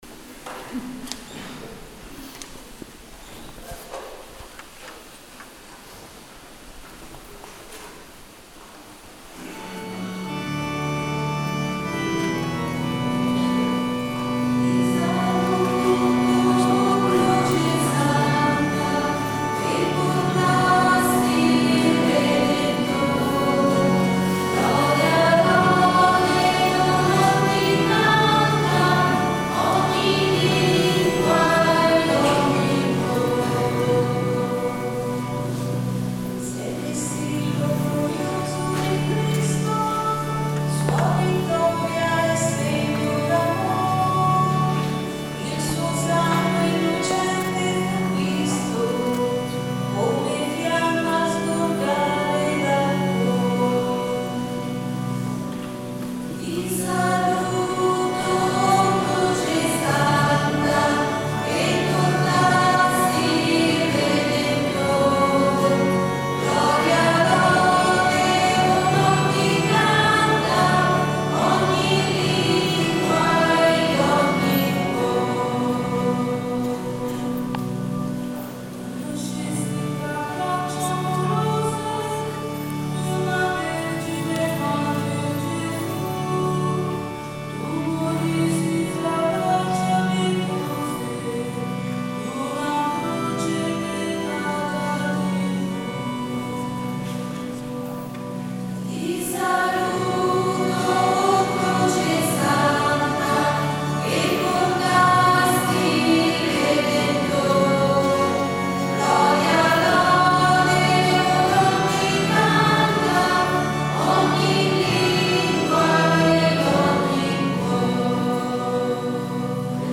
Adorazione Della Croce
Ti Saluto O Croce Santa, Scola Cantorum